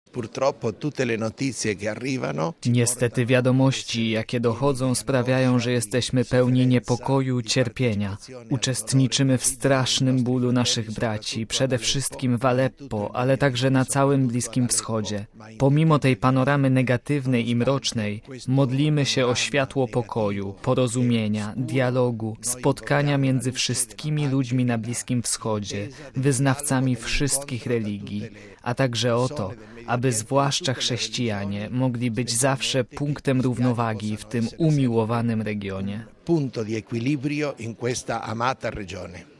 Uczestniczący w kongresie prefekt Kongregacji dla Kościołów Wschodnich kard. Leonardo Sandri jest pod wrażeniem cierpień znoszonych przez bliskowschodnich chrześcijan, szczególnie  syryjskich.